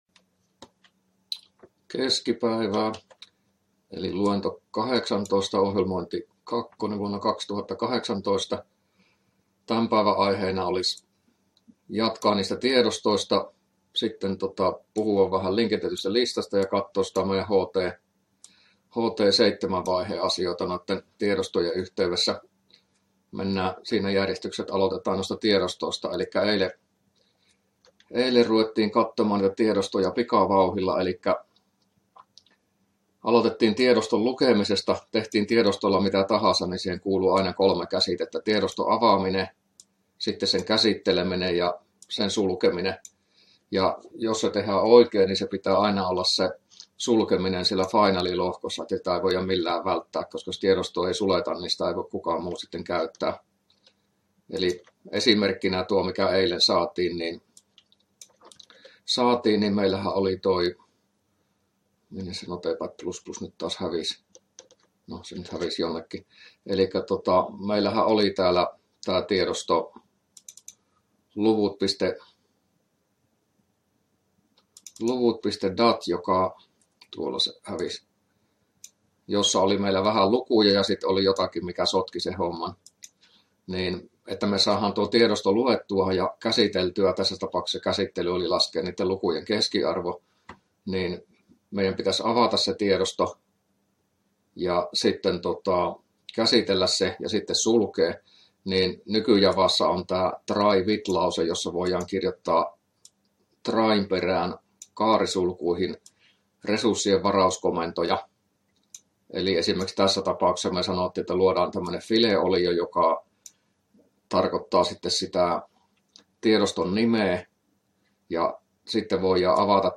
luento18a